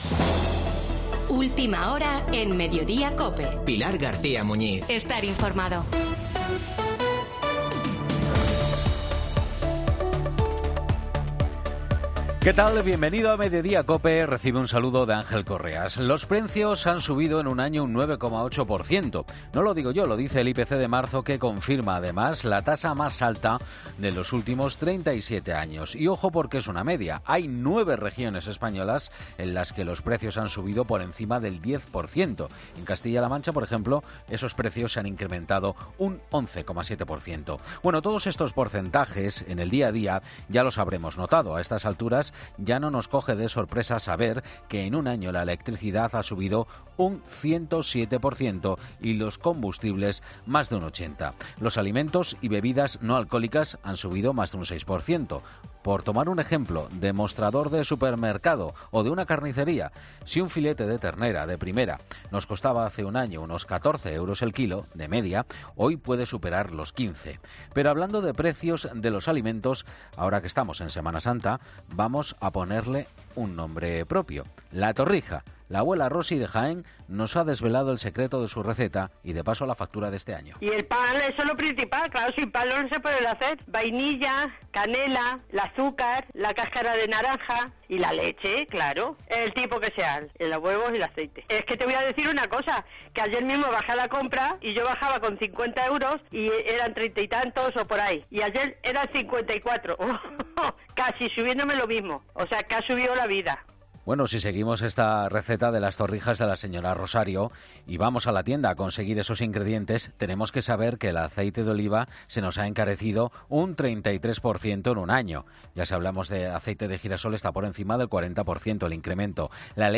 AUDIO: El monólogo de